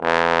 Index of /90_sSampleCDs/Roland L-CDX-03 Disk 2/BRS_Bs.Trombones/BRS_Bs.Bone Solo